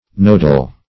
Nodal \Nod"al\, a.